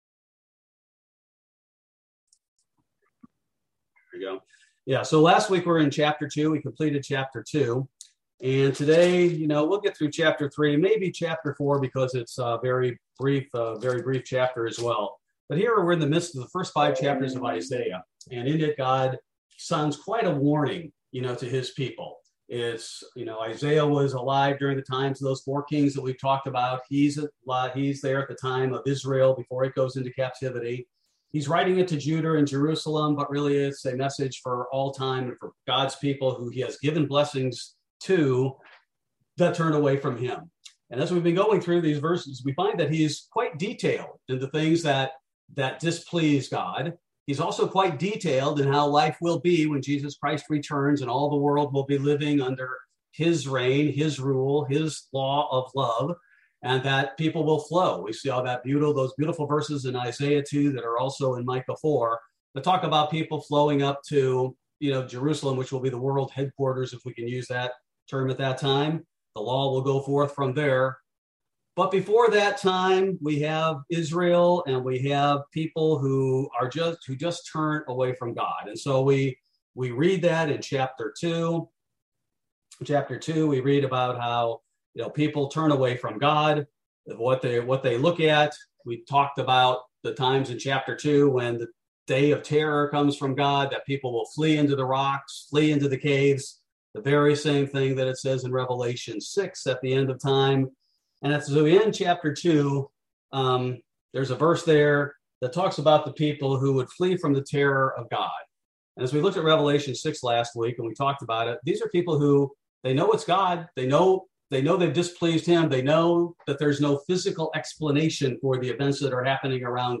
Bible Study: July 6, 2022